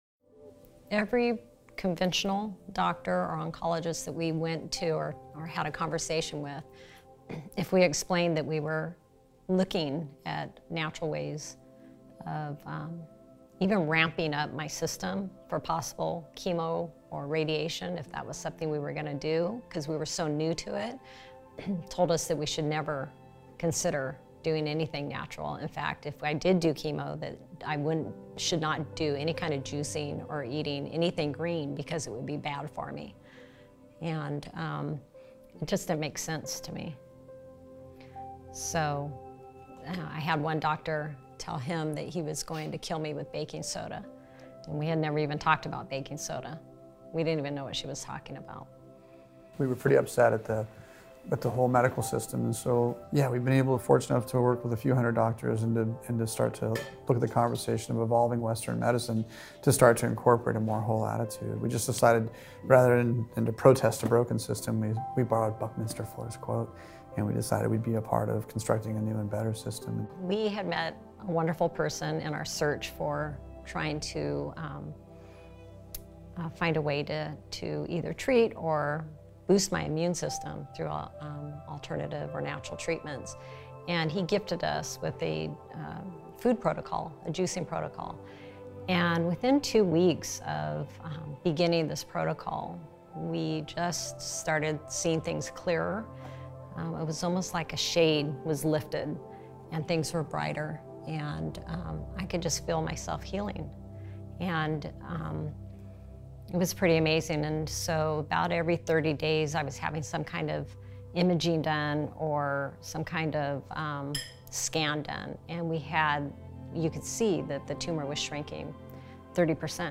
at The Truth About Cancer LIVE '16